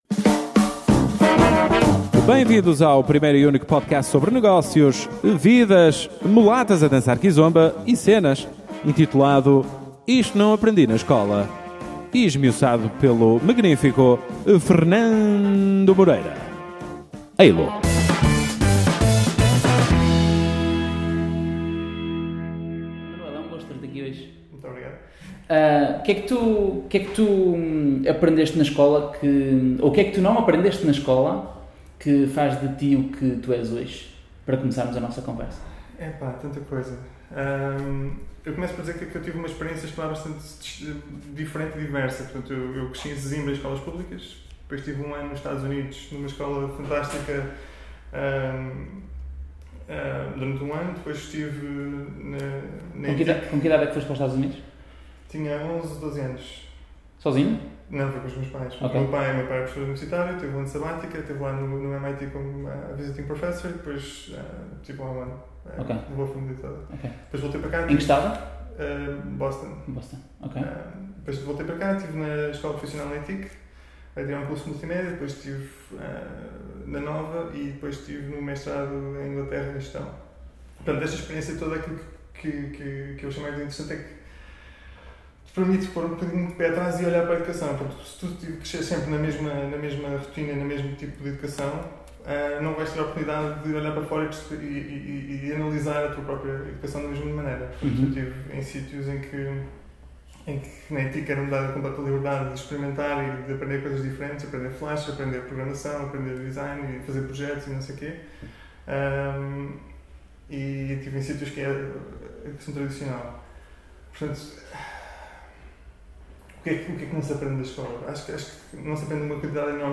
Dentro de duas semanas o som já será muito melhor :)
Isto não aprendi na escola é um podcast com conversas informais, onde regularmente convido pessoas com as quais tenho afinidade e lhes revejo autoridade, para me explicarem o que aprenderam nas suas vidas, que não na escola.